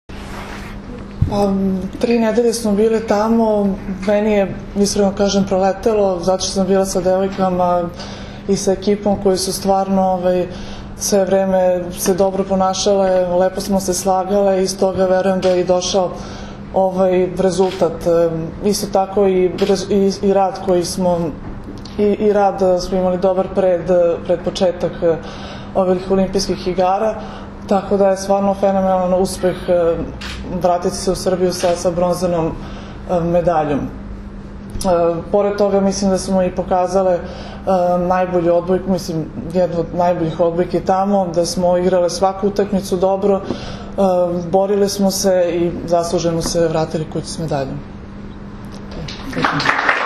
Olimpijski komitet Srbije je organizovao konferenciju za novinare u salonu “Beograd” aerodroma “Nikola Tesla”, na kojoj su se predstavnicima medija obratili Jelena Nikolić, kapiten seniorki Srbije, i Zoran Terzić, prvi trener seniorki Srbije.
IZJAVA JELENE NIKOLIĆ